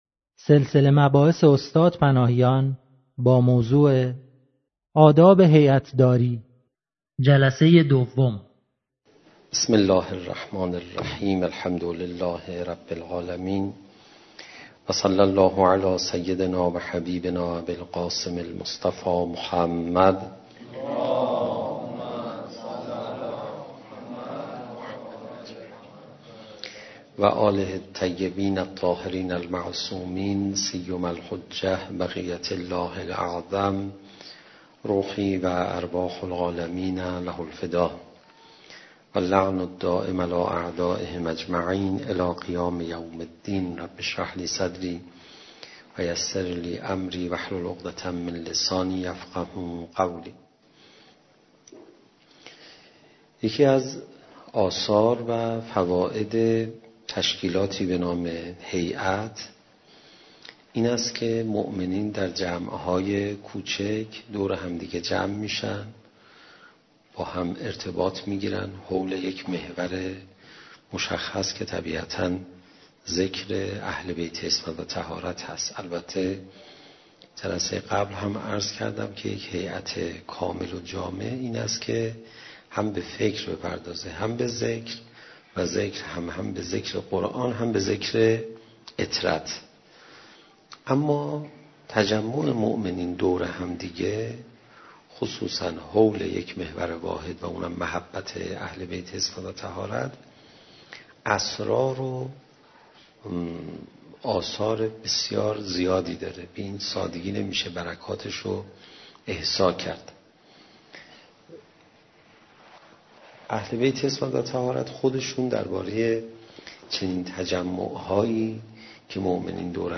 سخنرانی : حجت الاسلام علیرضا پناهیان با موضوع ((آداب هیئت داری))